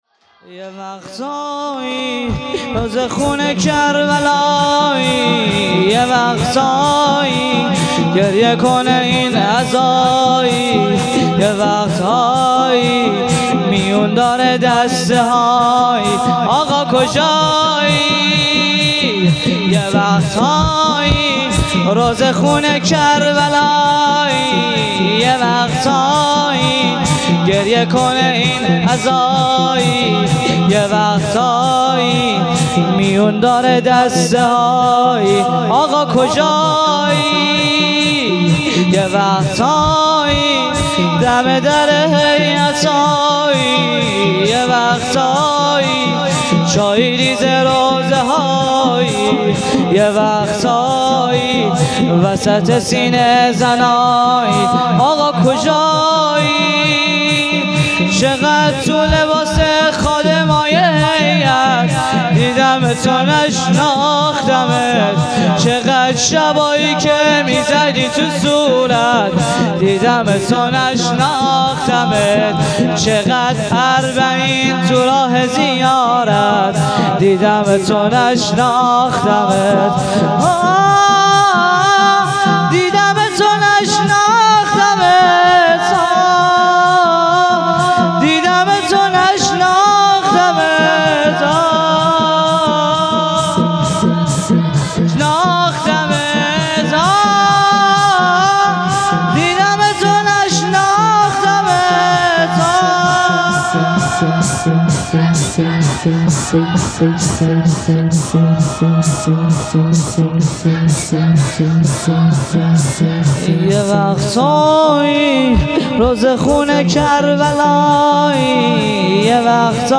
شور
روضه هفتگی